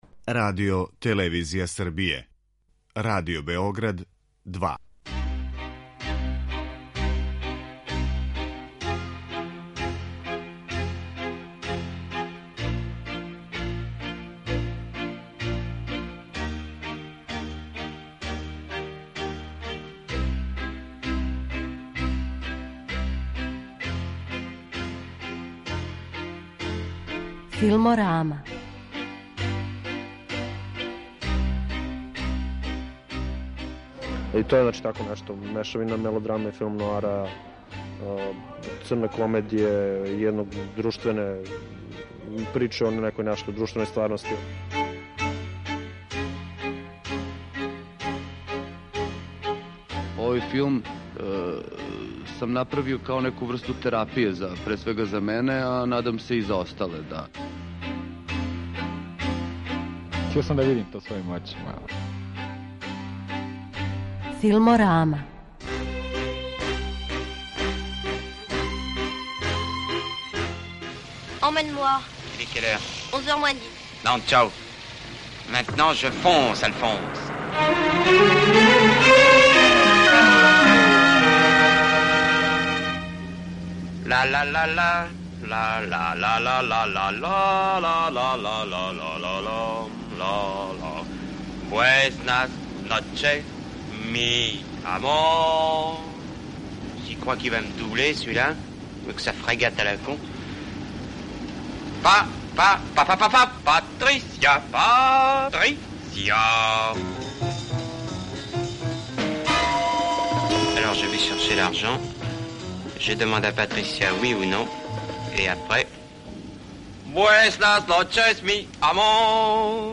У данашњој емисији чућемо и њих двоје, и њихов став о овом филму, документаристичкој манипулацији и себи као актерима.